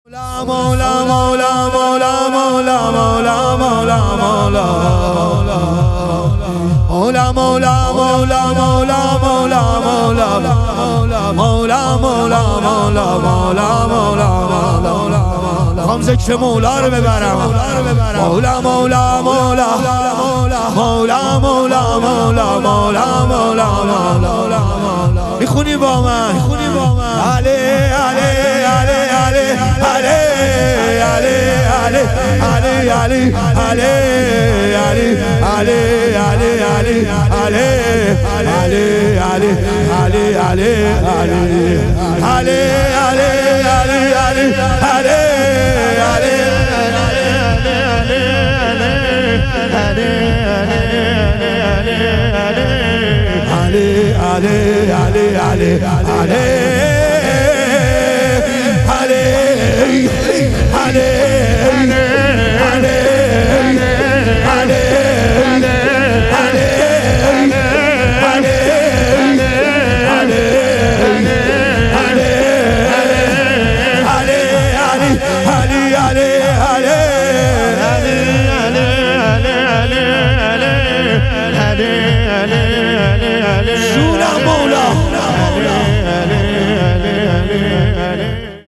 شام غریبان حضرت زهرا علیها سلام - شور